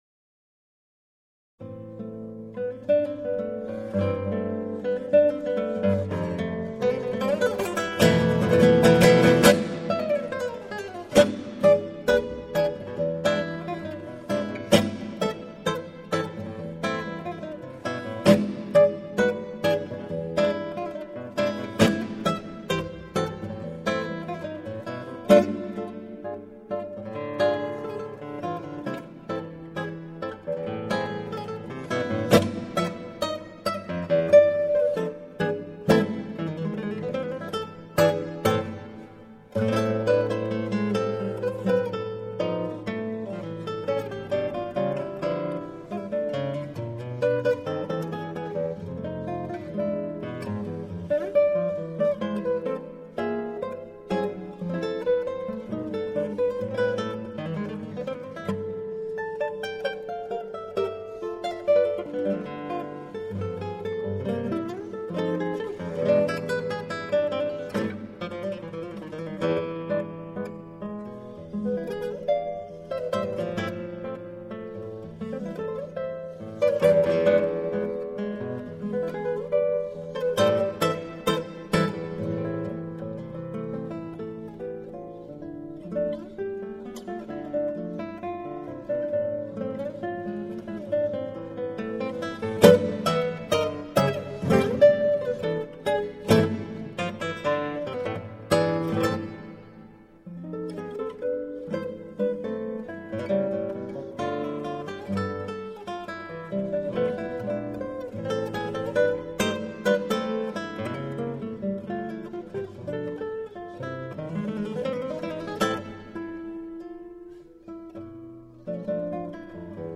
0188-吉他名曲康帕尔西塔.mp3